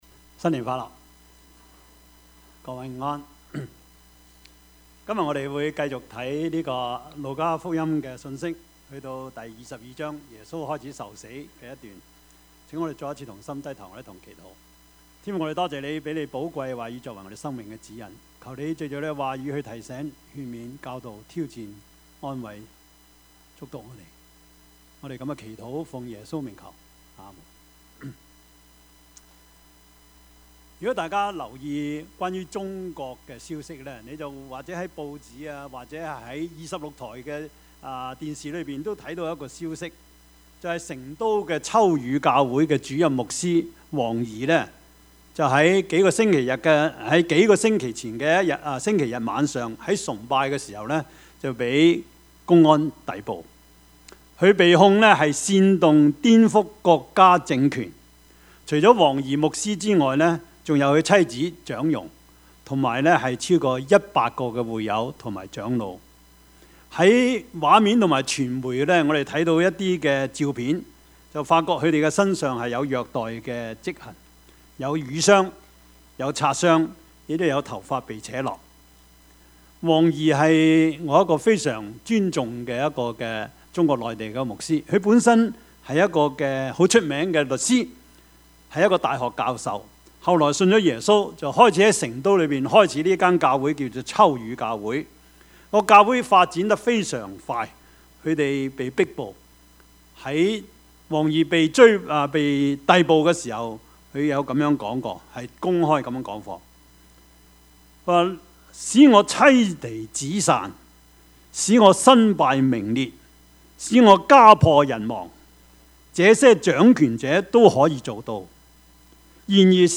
Service Type: 主日崇拜
Topics: 主日證道 « 以諾-與神同行 最後晚餐 »